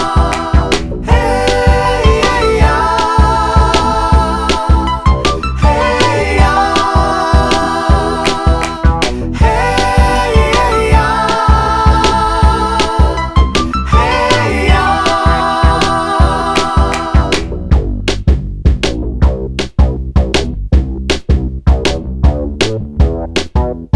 No Guitars R'n'B / Hip Hop 3:56 Buy £1.50